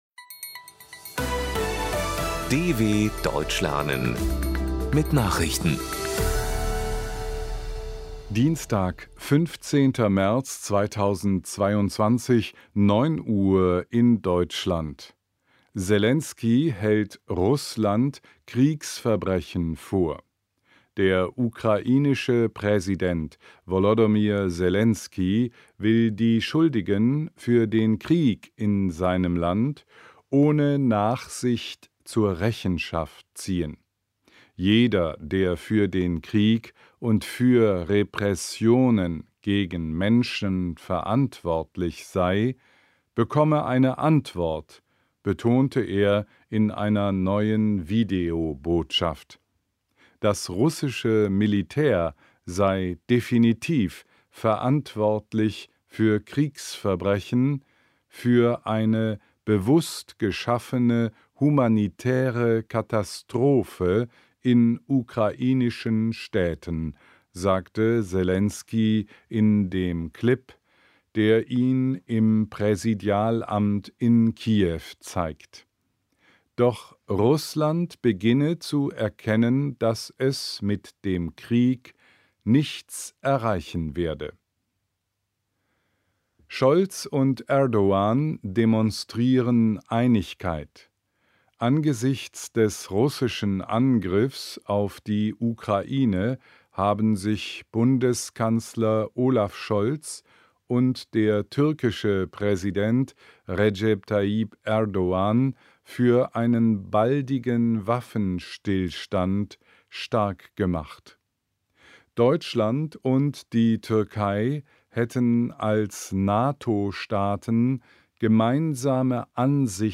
15.03.2022 – Langsam gesprochene Nachrichten
Trainiere dein Hörverstehen mit den Nachrichten der Deutschen Welle von Dienstag – als Text und als verständlich gesprochene Audio-Datei.